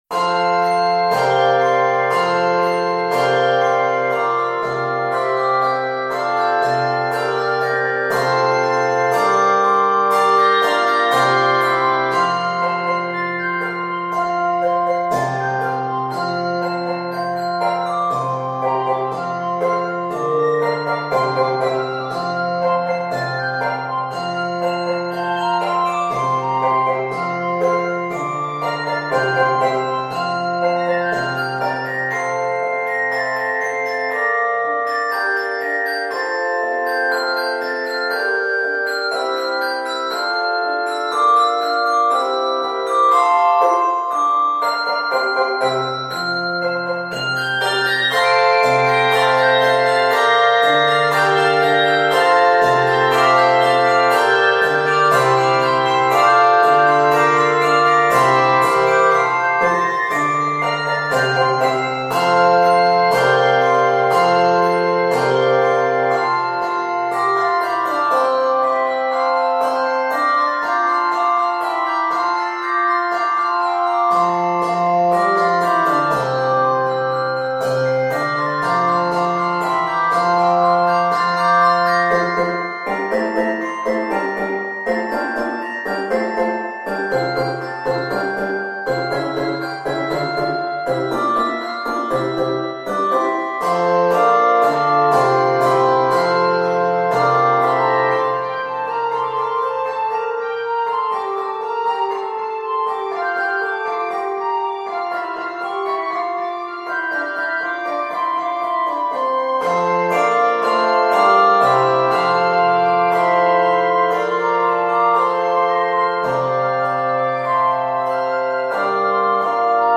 Octaves: 5
Varies by Piece Season: Christmas